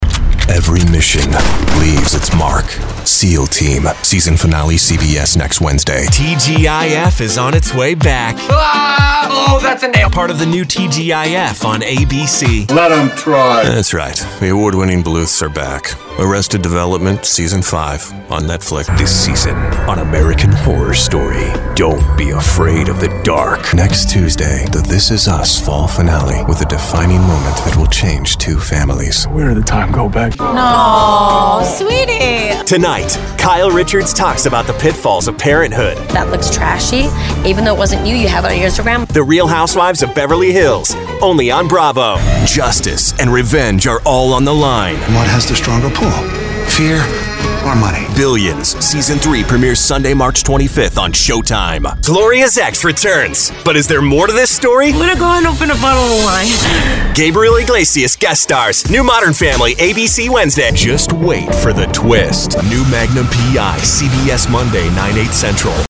Young Adult, Adult
standard us | natural
tv promos